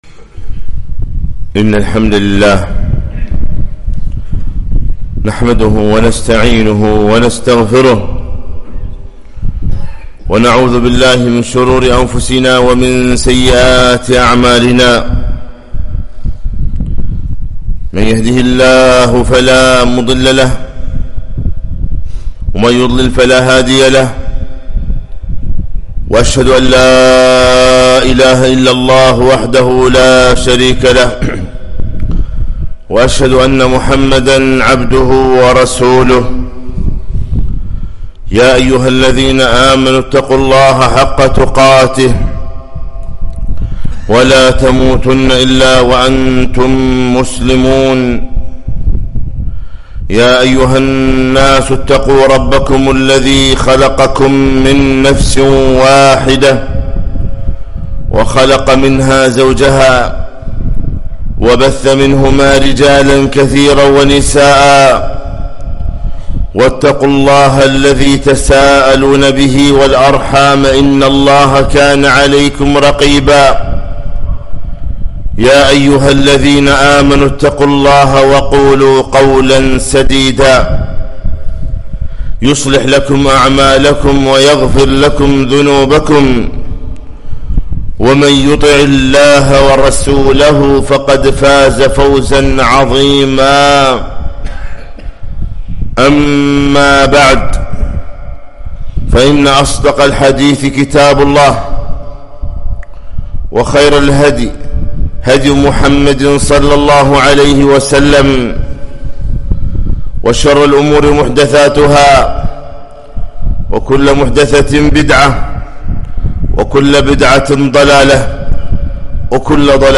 خطبة - ( يا نفس توبي )